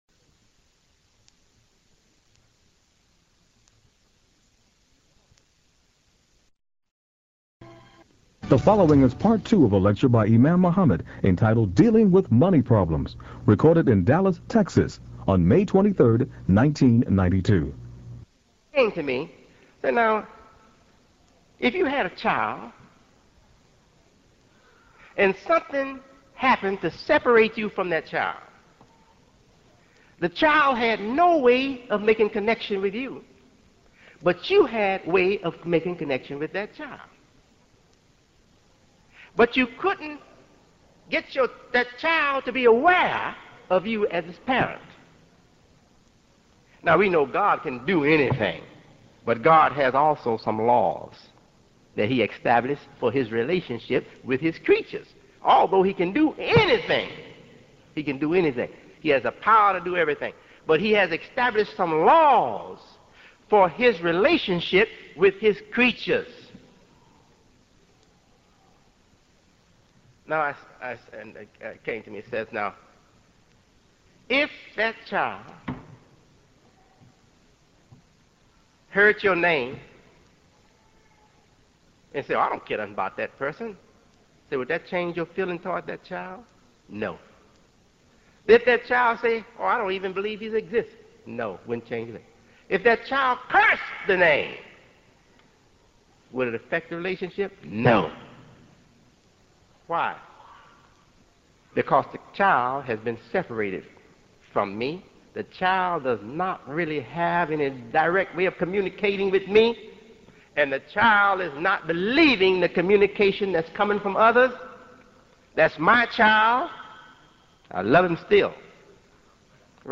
He (and others) have taken on the very important task of preserving the lectures of Imam W. Deen Mohammed by transferring them from old cassette tapes to mp3 format.